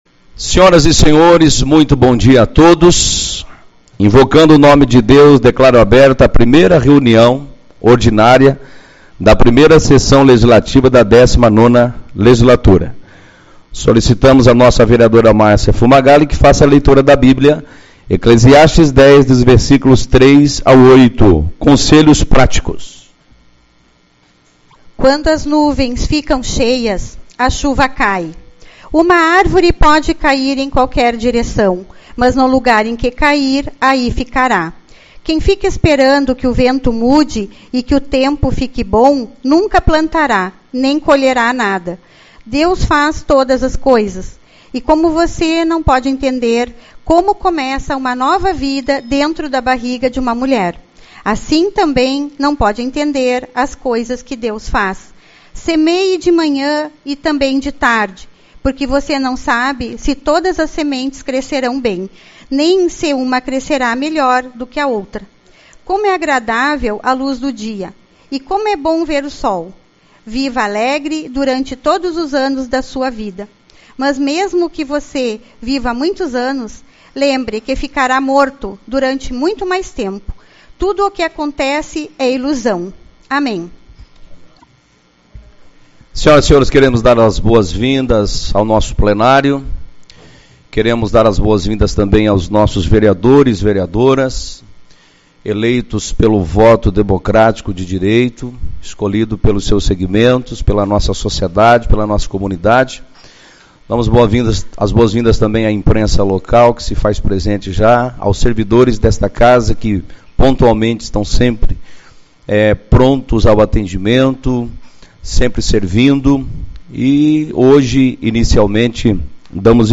04/02 - Reunião Ordinária